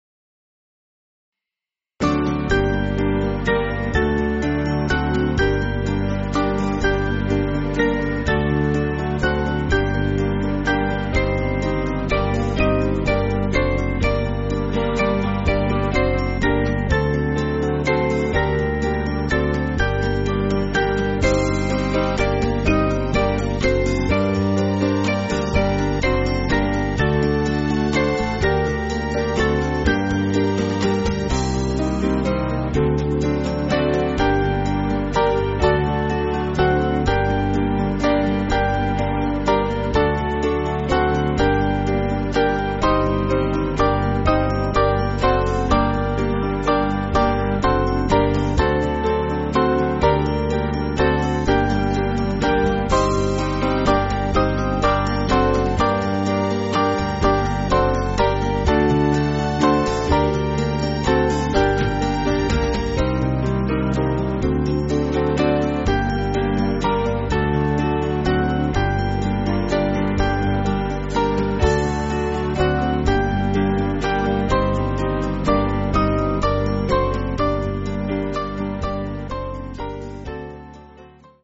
Small Band
(CM)   6/Gm